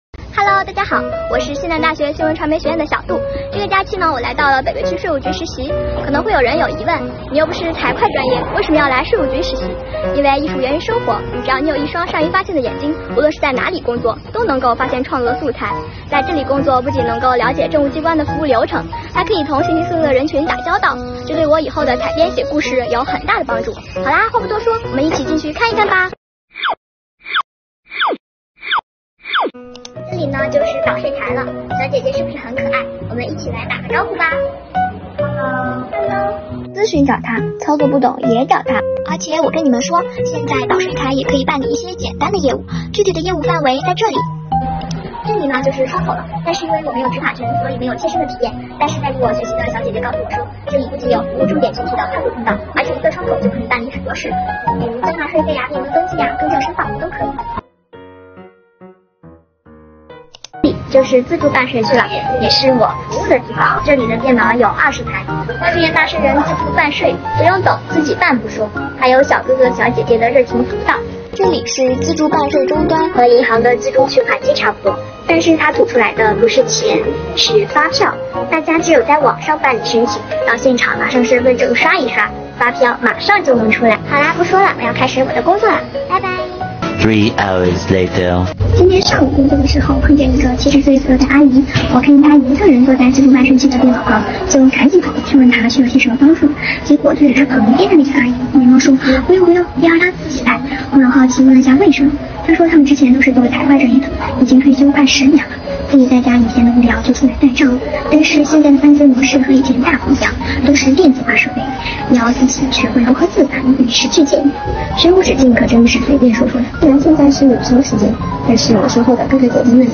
Vlog丨我的实习日记——记录在税厅工作的点滴